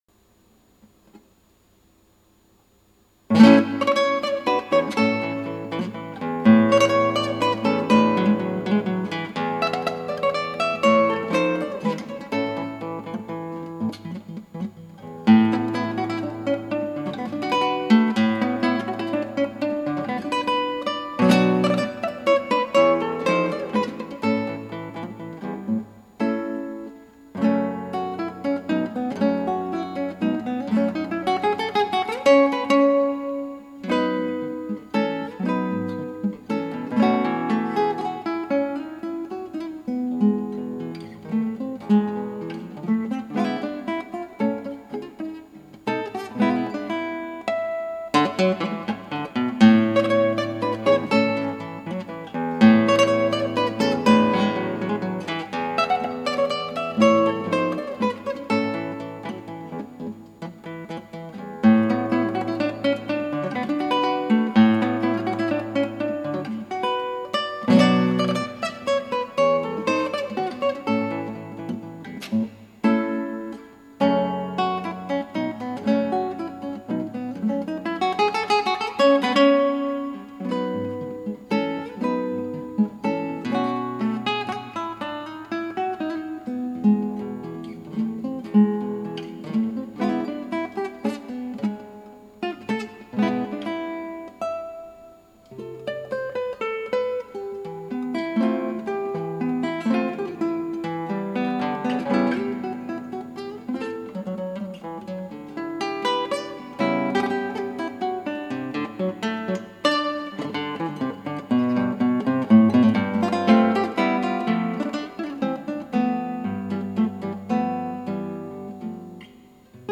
クラシックギター　ストリーミング　コンサート
途中止まってこそいないけど、脳天白紙状態になってる個所がいくつも見られます。